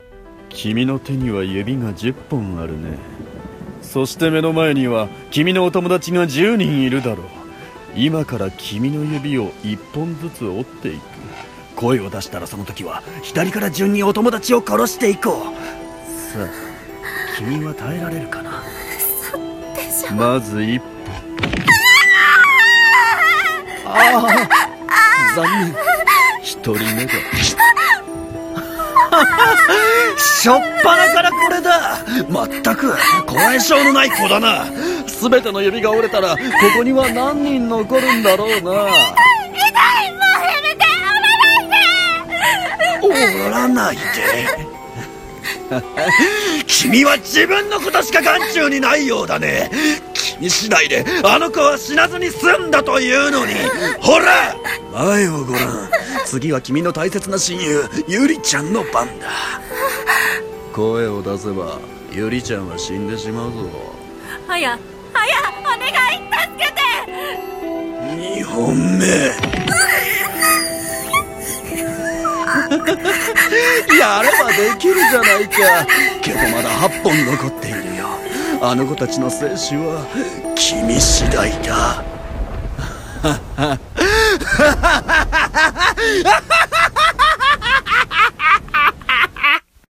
【R15】指折り殺人鬼【ホラー声劇】